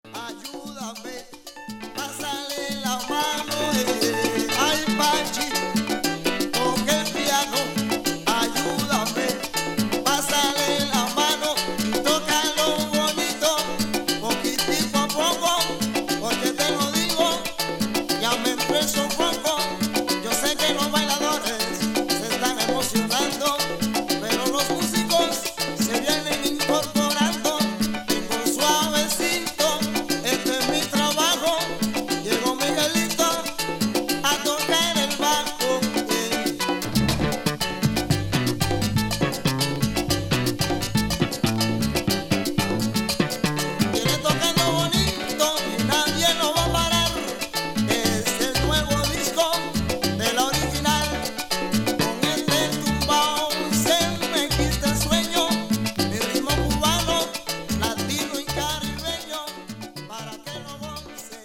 ルンバ , Son , Guaracha , Salsa 中心のLP 1989年作